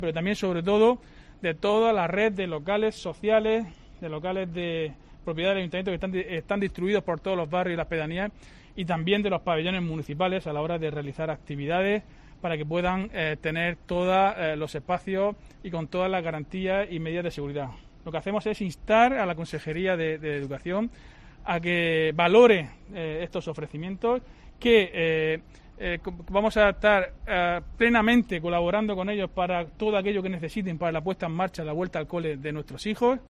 Diego José Mateoe, alcalde de Lorca sobre educación